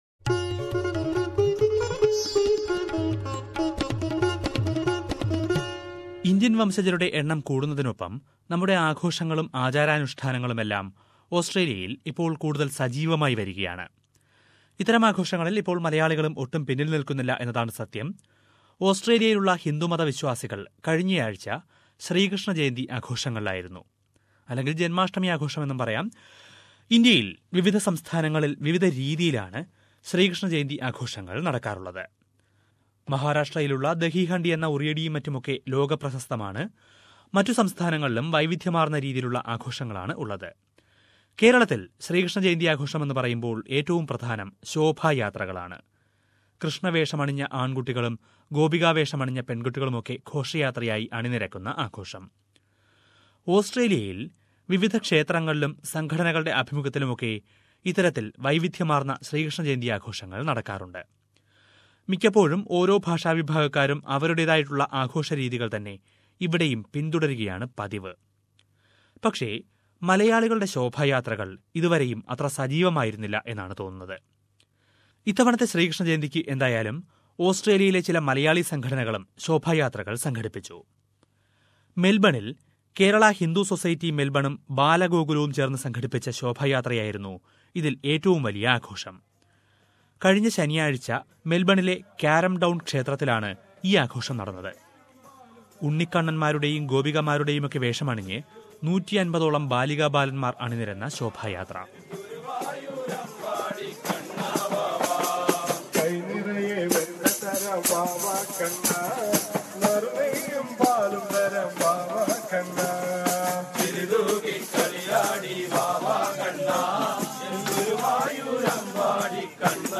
Here is a report on the Sreekrishna Jayanthi celebrations of Australian Malayalees.